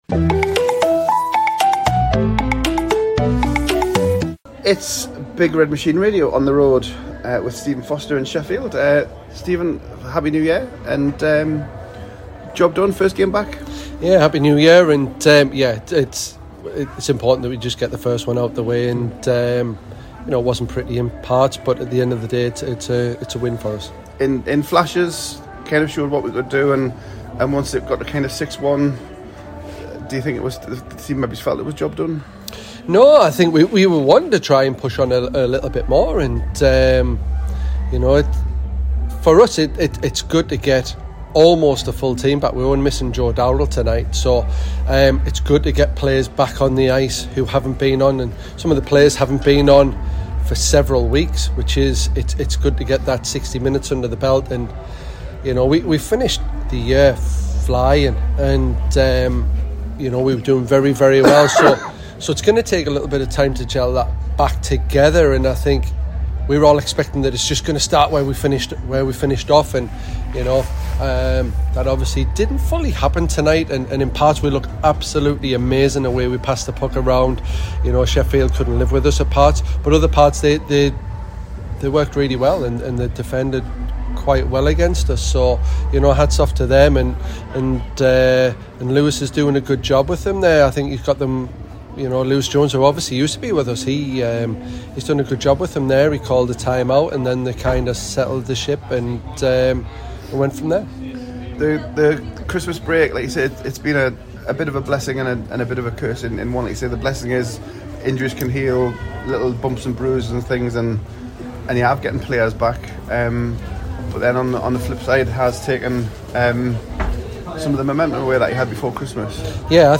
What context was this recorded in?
Big Red Machine Radio first foots into Ice Sheffield for Stars first 2024 clash.